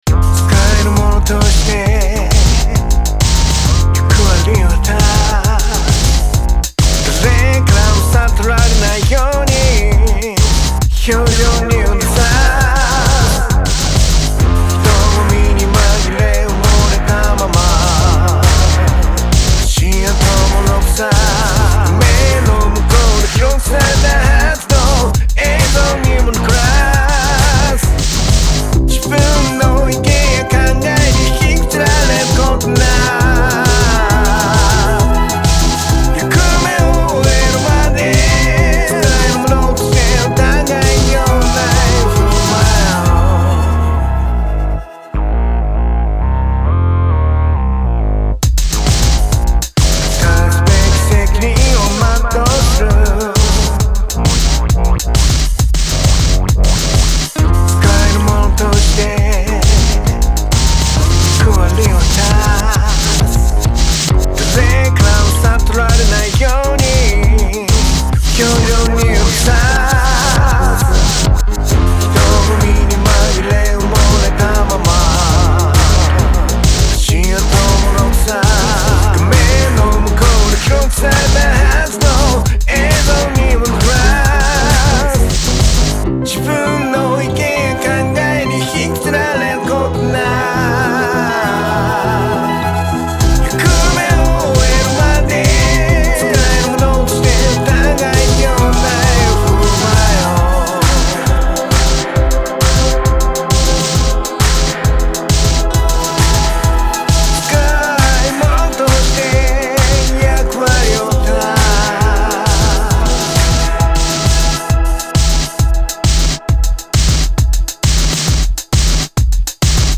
毎日1曲、新曲つくってアレンジ＆録音したものを日々アップロード中。